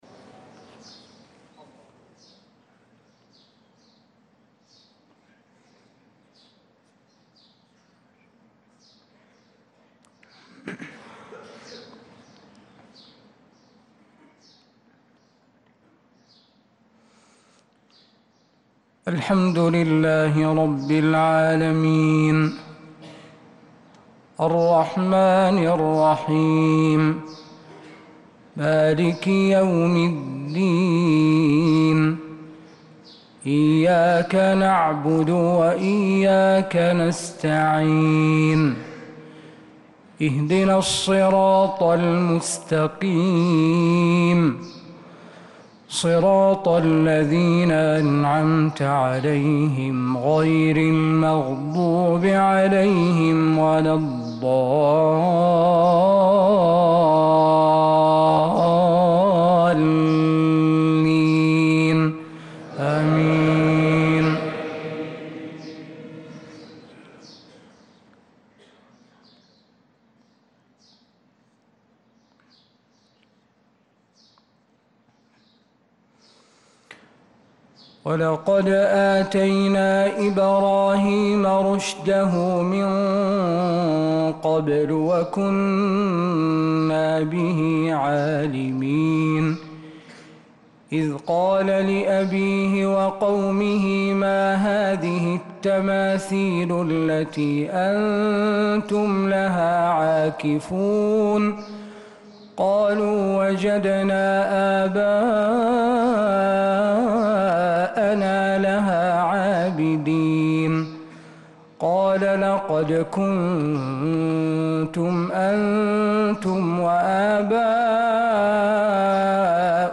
صلاة الفجر